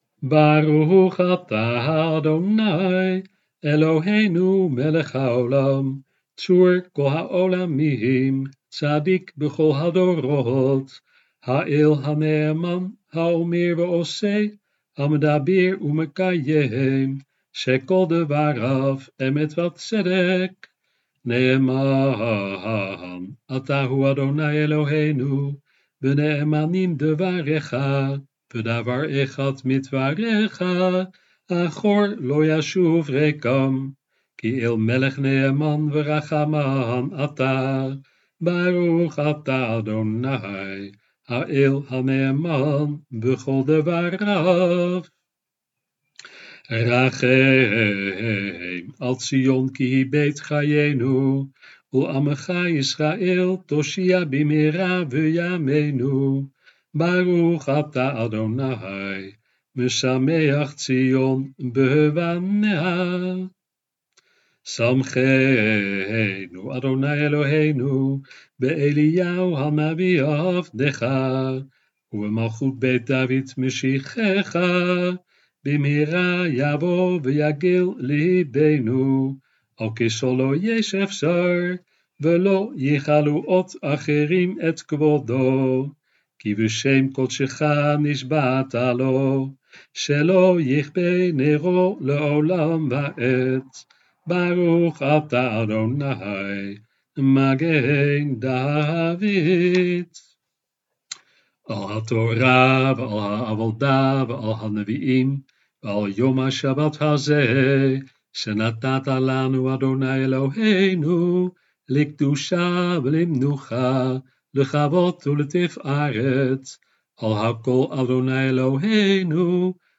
De opnames van teksten zijn in de mannelijke vorm.
Berachot bij het lezen van de Haftara